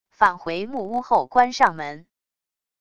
返回木屋后关上门wav音频